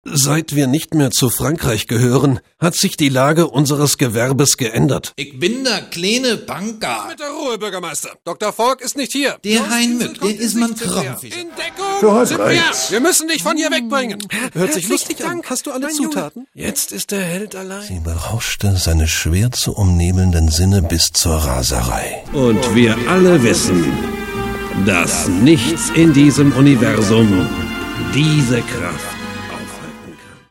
deutscher Sprecher für Nachrichten, TV-Features, Magazinbeiträge, Imagefilme, Produktfilme, Schulungsfilme, Werbespots, On-Air-Promotion, Hörspiele, Hörbücher, Synchronrollen.
Kein Dialekt
Sprechprobe: eLearning (Muttersprache):